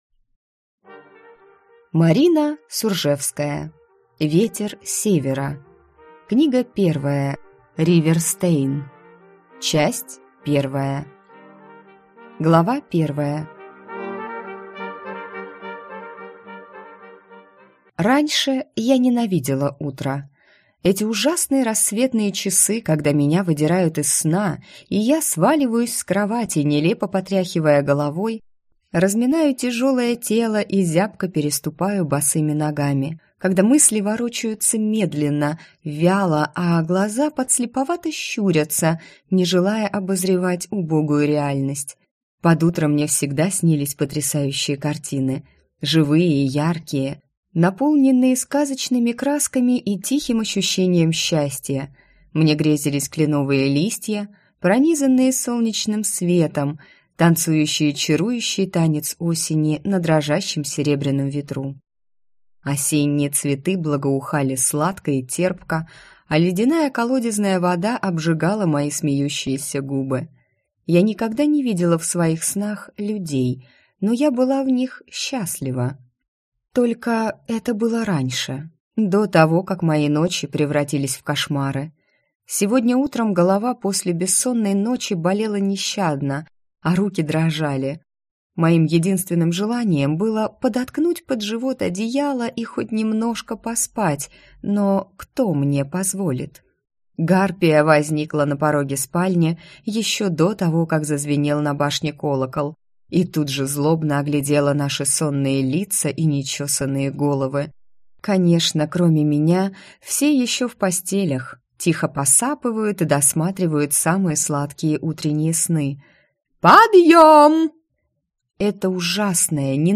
Аудиокнига Ветер Севера. Риверстейн - купить, скачать и слушать онлайн | КнигоПоиск